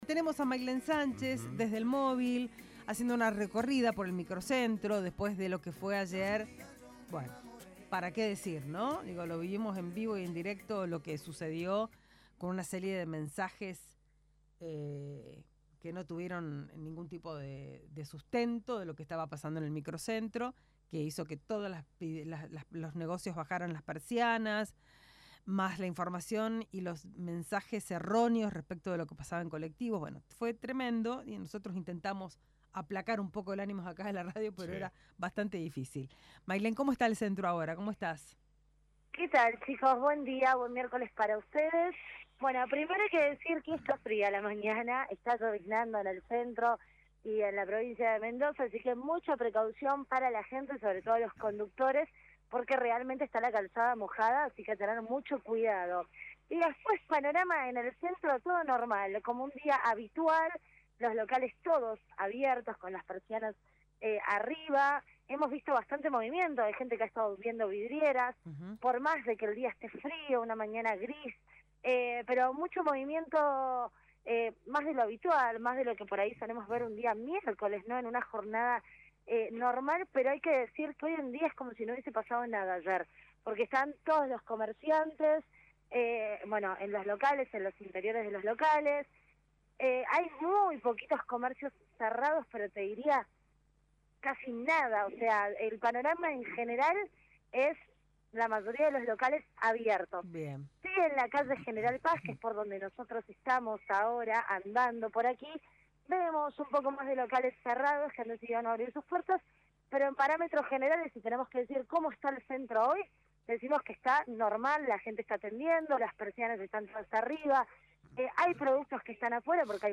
LVDiez - Radio de Cuyo - Móvil de LVDiez - recorrida por microcentro mendocino